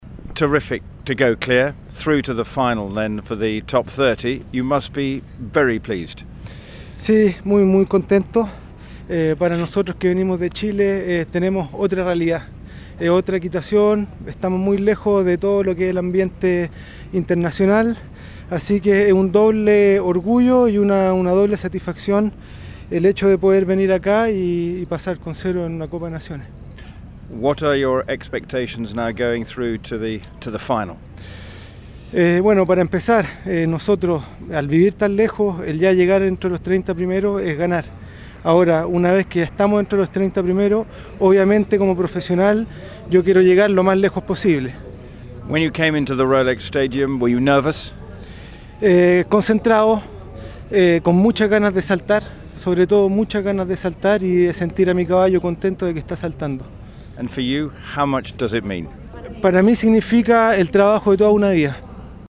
WEG Show Jumping: Interview Audio Links